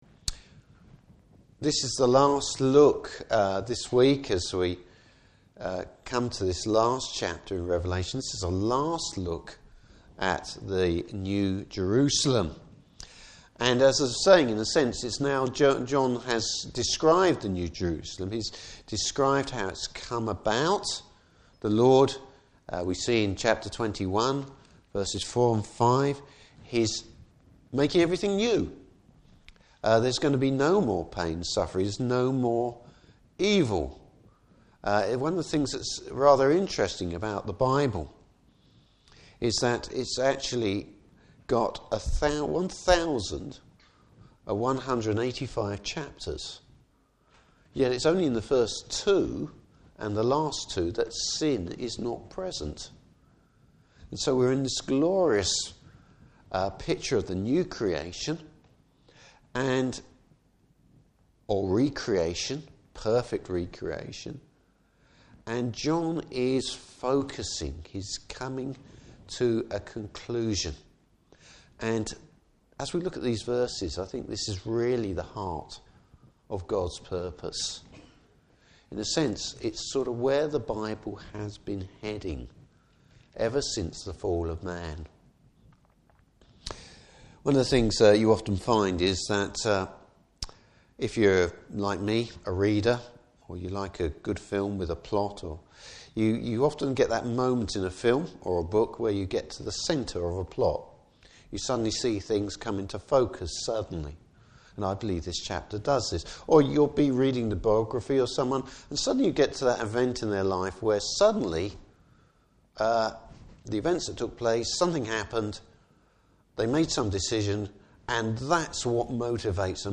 Service Type: Evening Service Bible Text: Revelation 22:1-6.